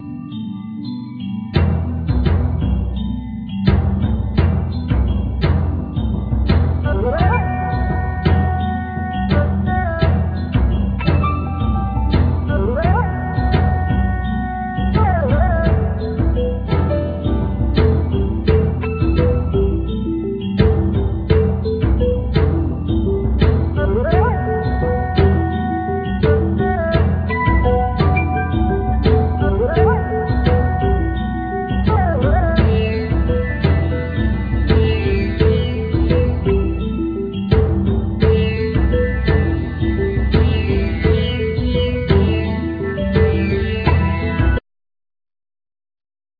Guitar,Guitar Synth,Programming
Sitar
Tabla